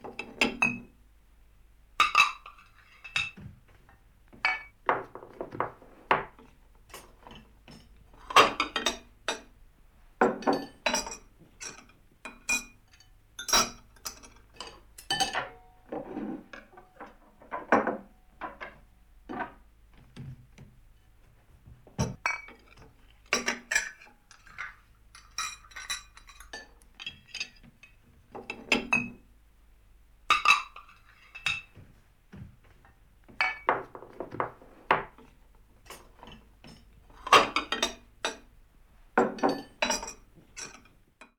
household
Cutlery and Crockery onto Table